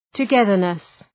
Προφορά
{tə’geðərnıs}